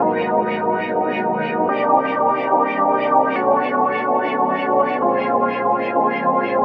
K-6 Pad 2 LFO.wav